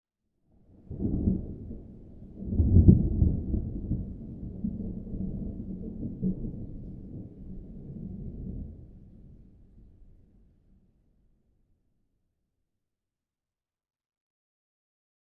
Nature Thunder Sound Effect Free Download
Nature Thunder